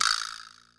VibraMte.wav